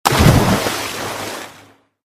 water for drama Meme Sound Effect
This sound is perfect for adding humor, surprise, or dramatic timing to your content.